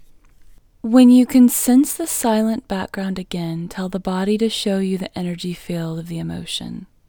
LOCATE Short IN English Female 12